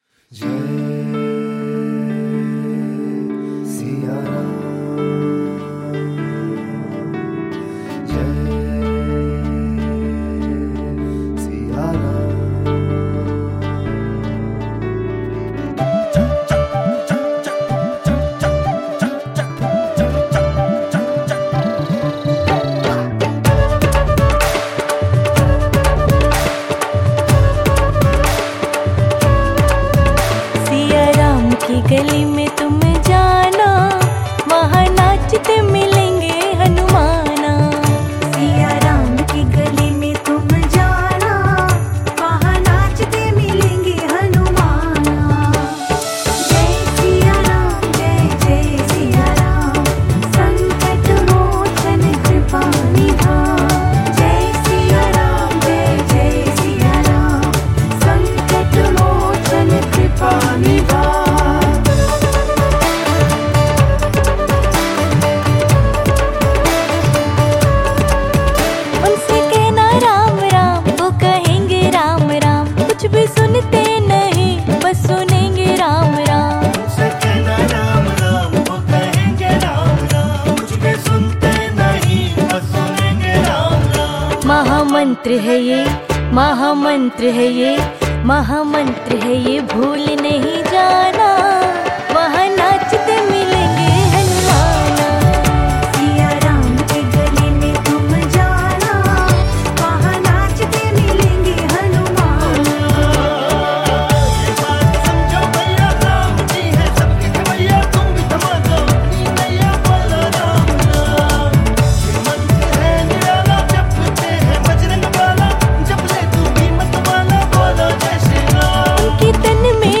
Releted Files Of Ram Ji Bhajan Mp3 Song